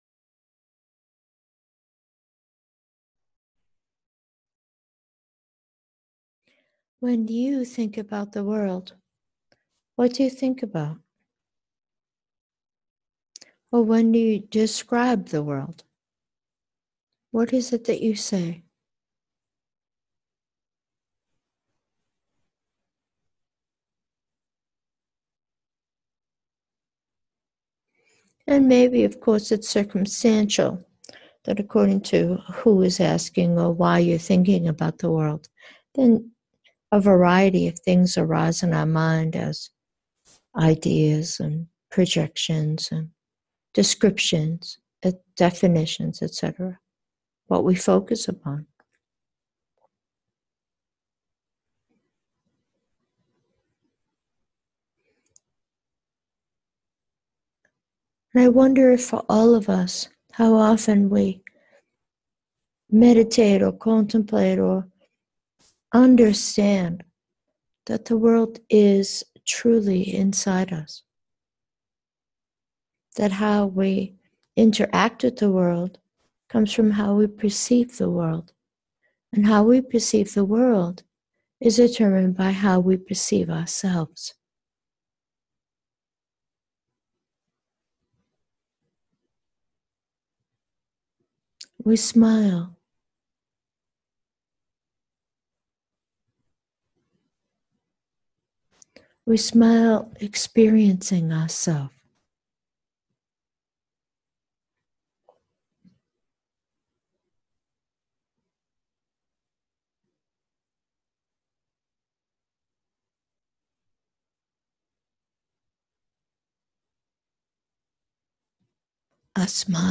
Meditation: disappear